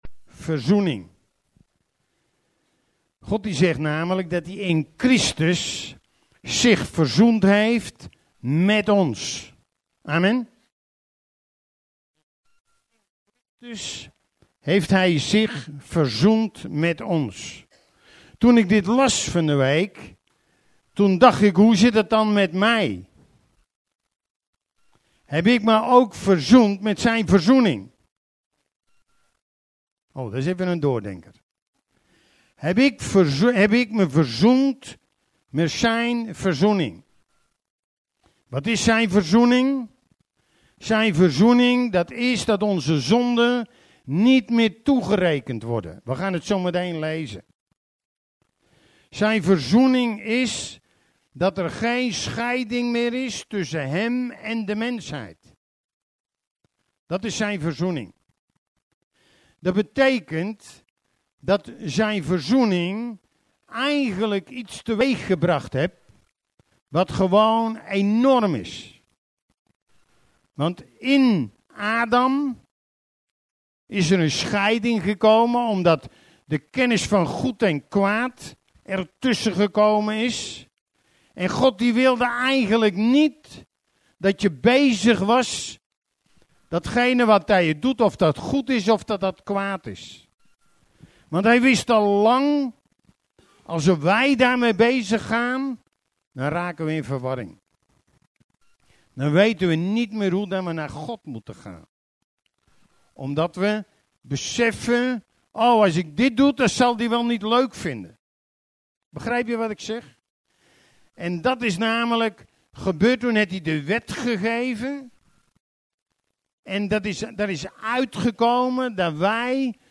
Preek